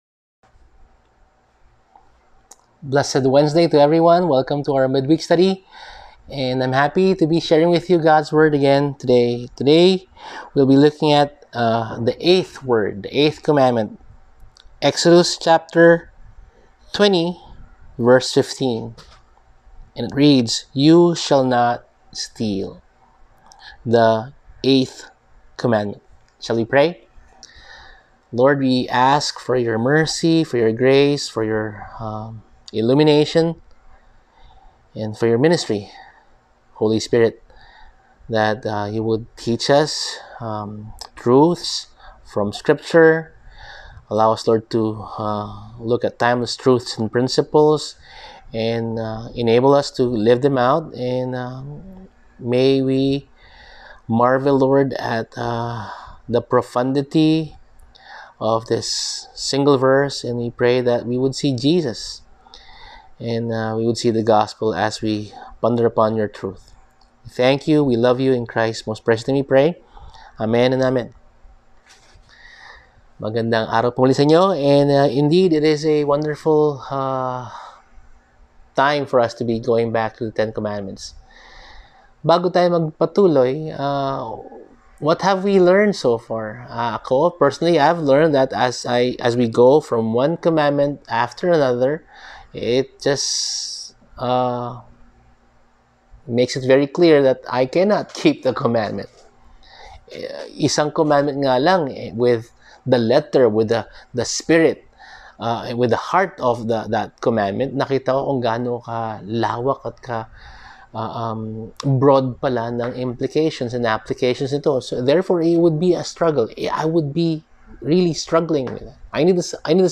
Service: Midweek Sermon